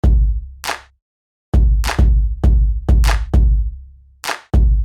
踢腿和拍手
描述：带有踢腿和手拍的简单循环鼓
标签： 100 bpm Hip Hop Loops Drum Loops 829.20 KB wav Key : Unknown
声道立体声